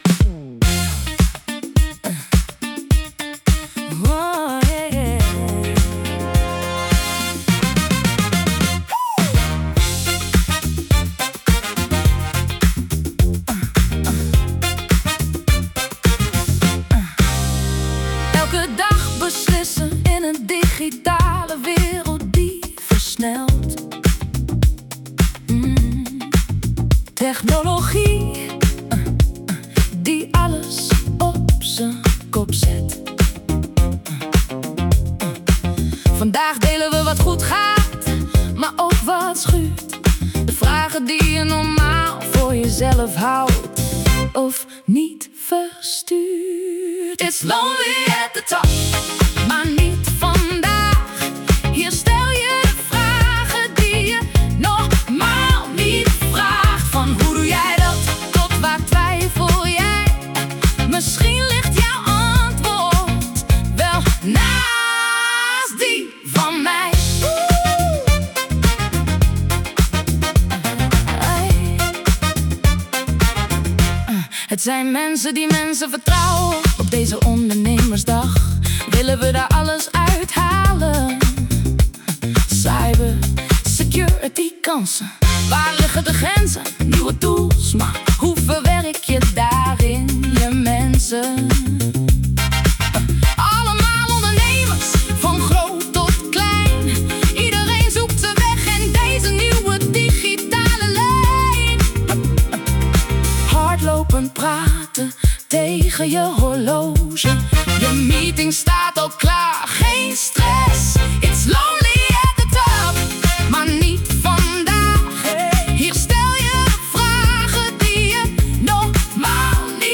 AI-lied, samenvatting van de NLdigital Ondernemersdag 2026Downloaden